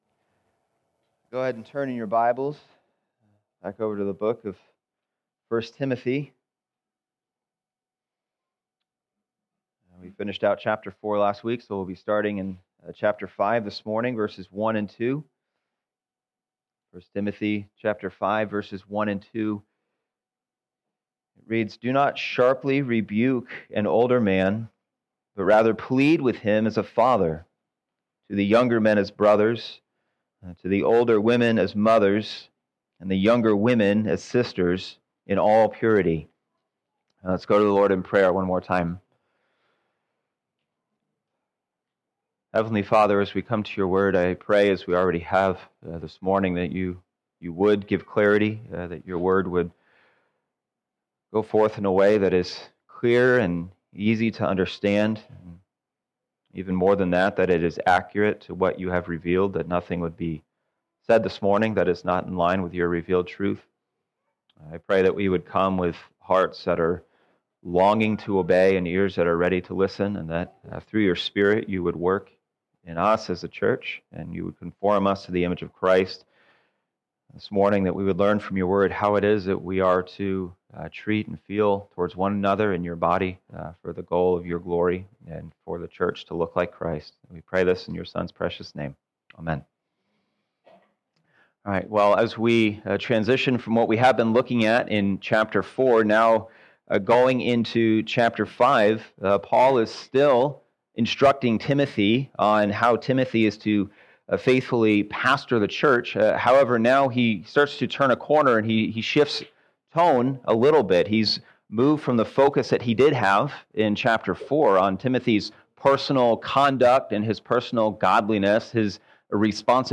Sunday Morning - Fellowship Bible Church